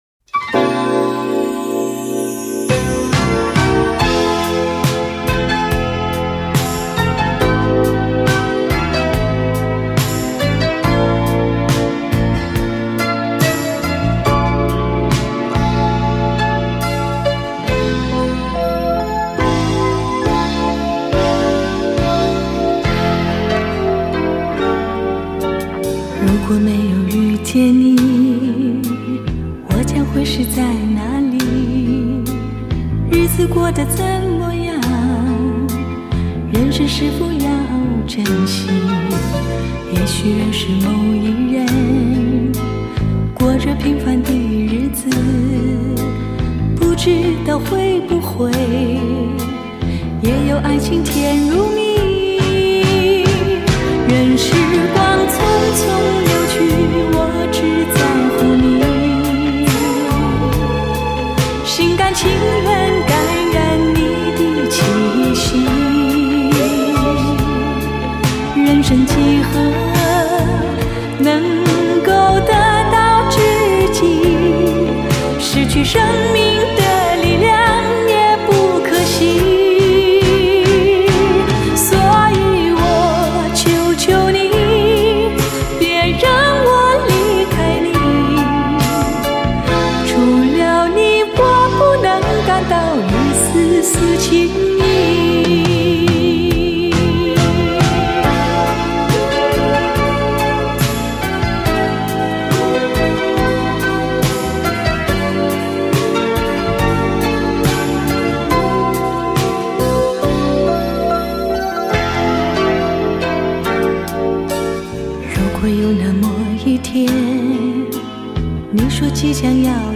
DXD重新编制